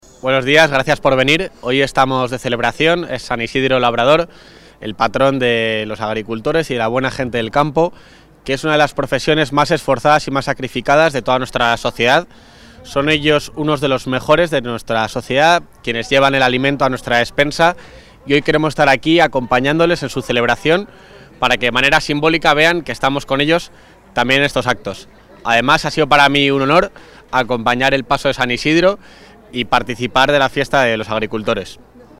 Declaraciones del vicepresidente de la Junta.